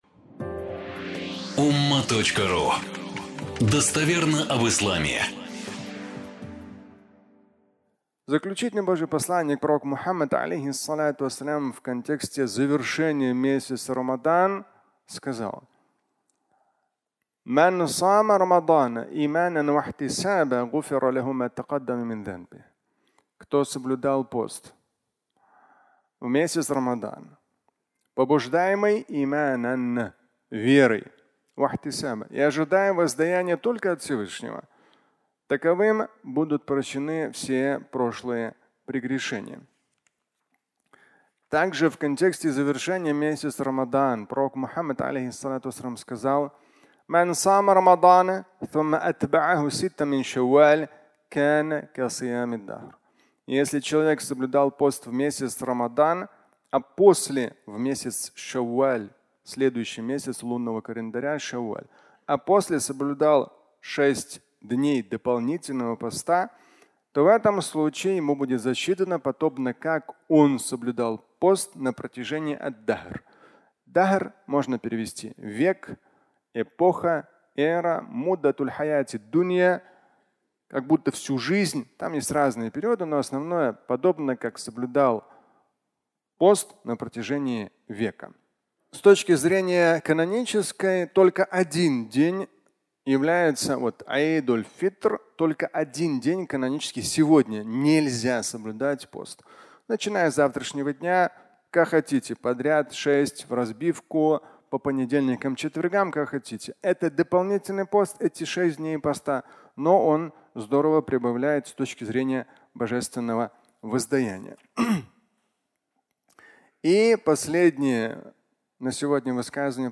Длинная жизнь (аудиолекция)
Фрагмент праздничной проповеди